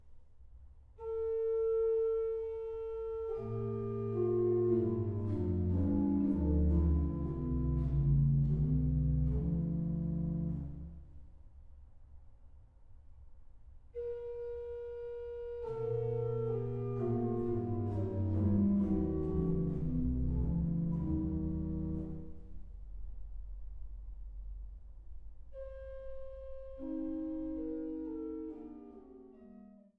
CD1 Dreymann-Orgel der Laurentiuskirche Trebur